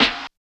62 SNARE 3-L.wav